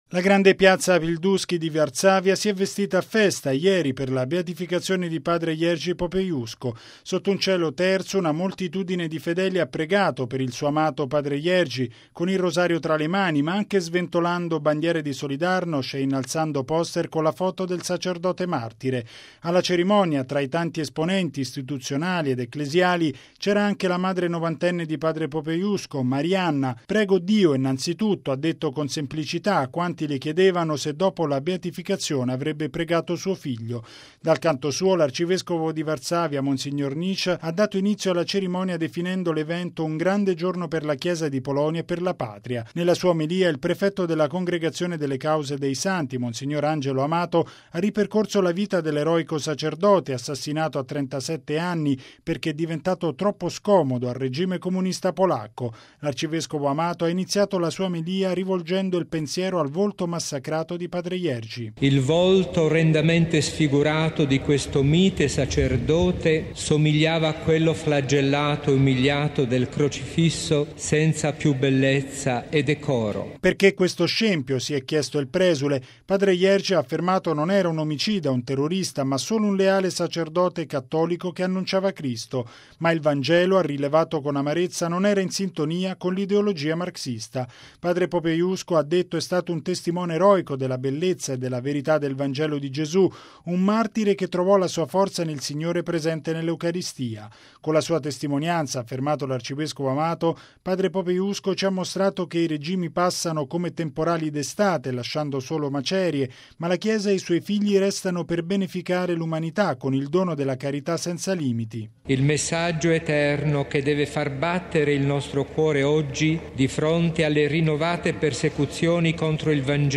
Un evento che ha raccolto 150 mila fedeli e che ha visto la concelebrazione di oltre cento vescovi e duemila sacerdoti.